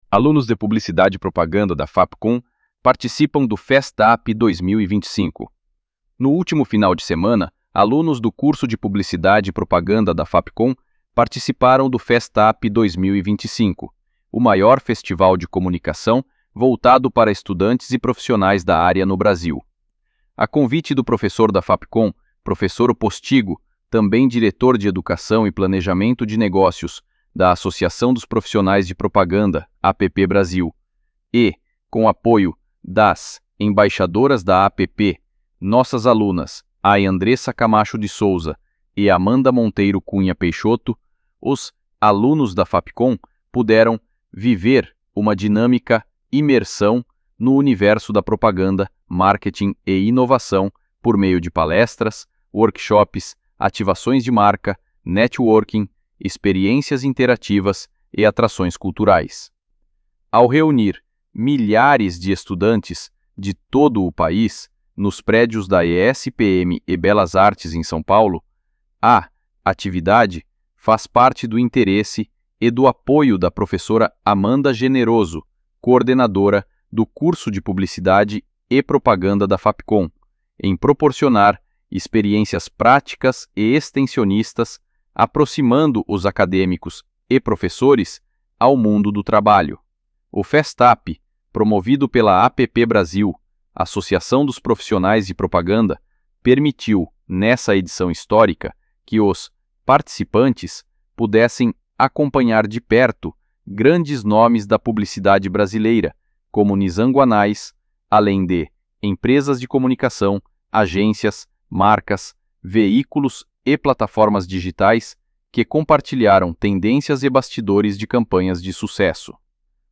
freepik__voiceover-generator__25082.mp3